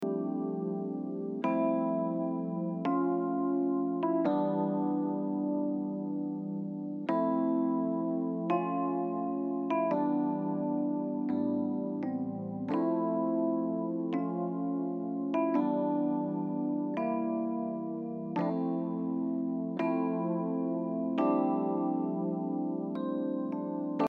まずはドライのエレピトラック。
モジュレーションがかかった状態で録音されているのでこれ以上モジュレーションはかけなくてもよさそうですね。